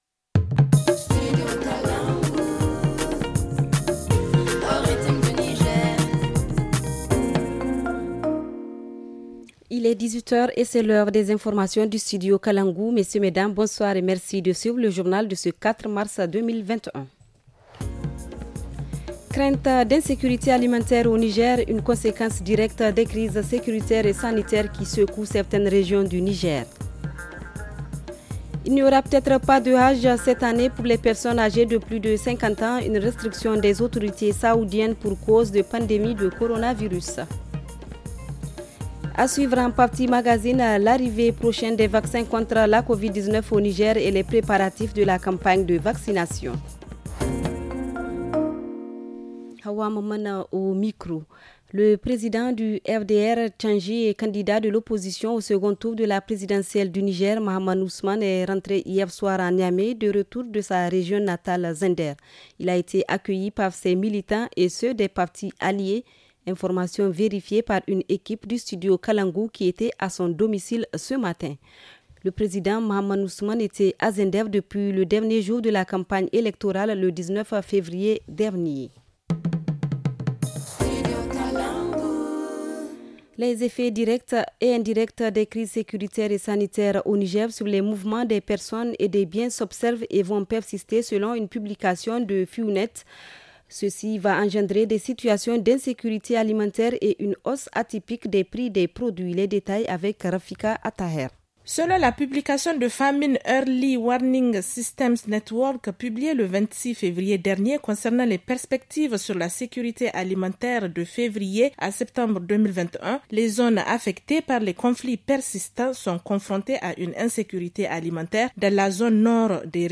Le journal du 04 mars 2021 - Studio Kalangou - Au rythme du Niger